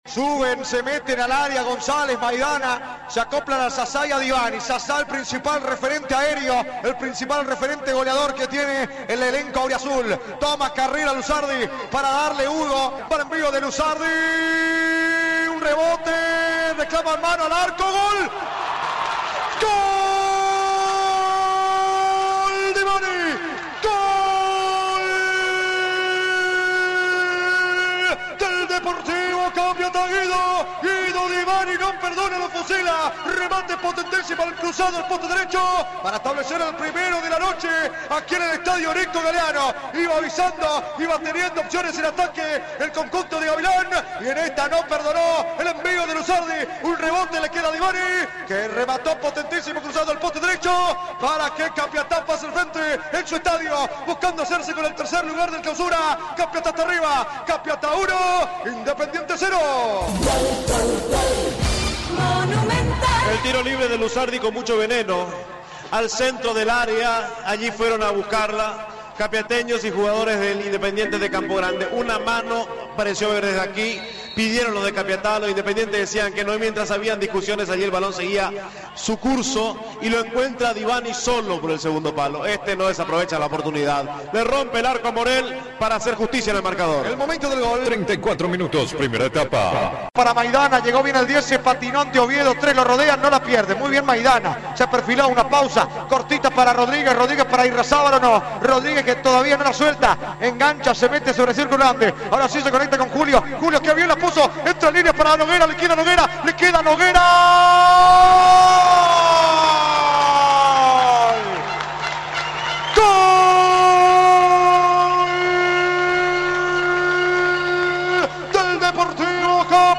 38' ST -Relato
Comentario